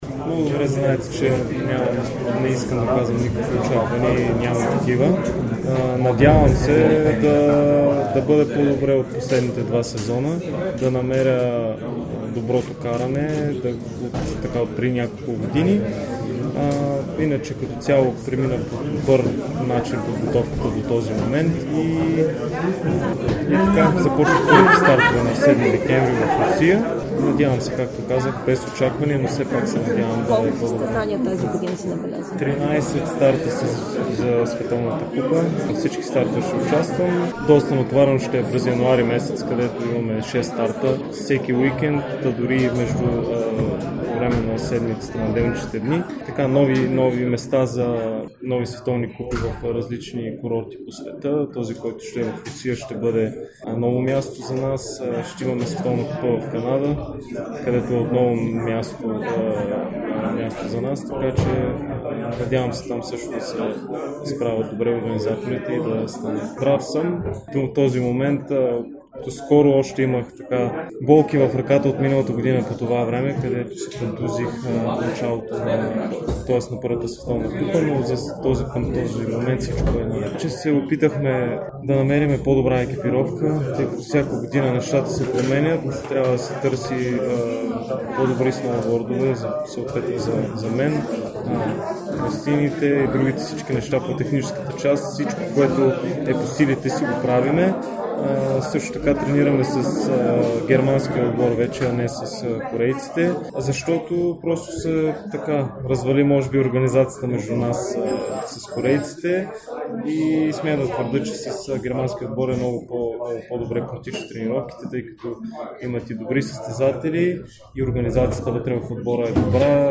Двама от най-добрите ни състезатели Алберт Попов – ски алпийски дисциплини и Радослав Янков – сноуборд, бяха на събитието за медиите и разкриха какво им предстои през новия сезон.